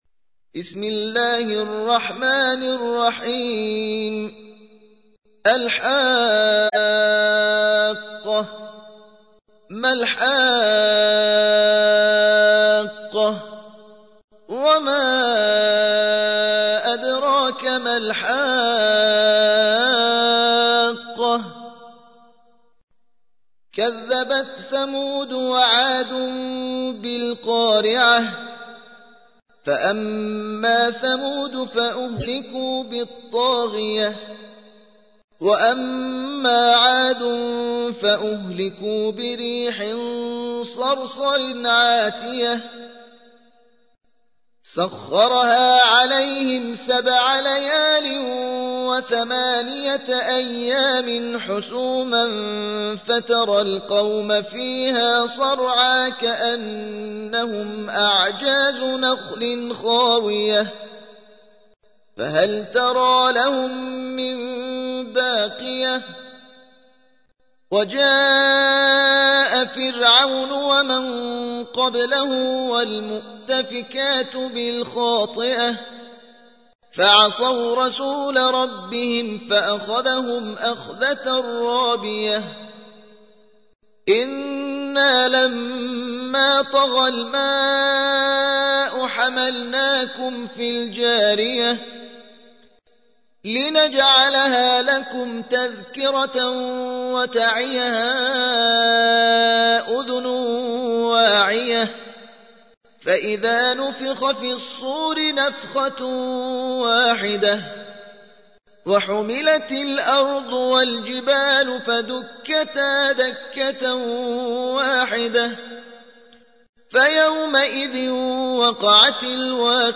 69. سورة الحاقة / القارئ